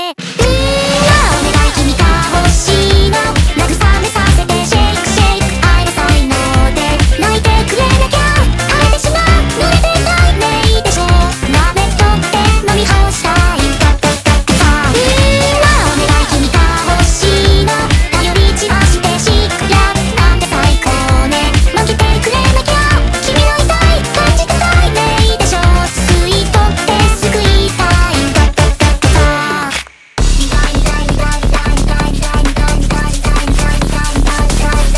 Жанр: Поп музыка
J-Pop